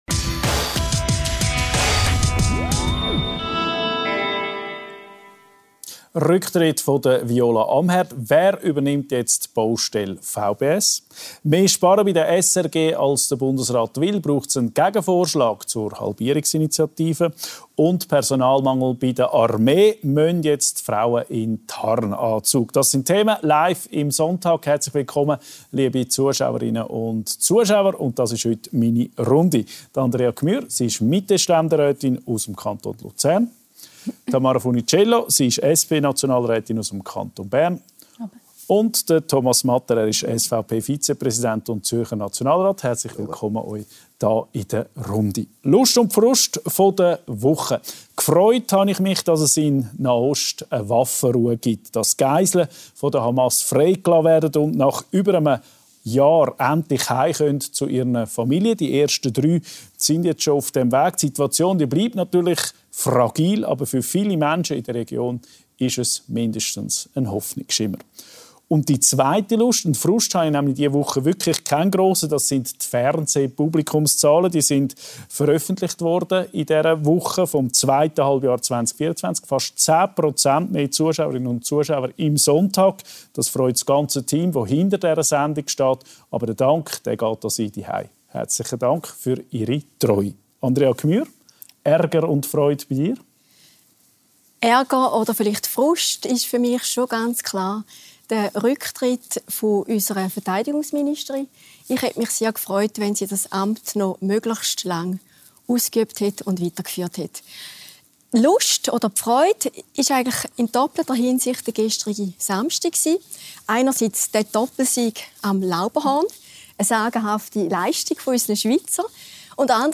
• Tamara Funiciello, SP-Nationalrätin Kanton Bern • Thomas Matter, SVP-Nationalrat Kanton Zürich • Andrea Gmür-Schönenberger, Mitte-Ständerätin Kanton Luzern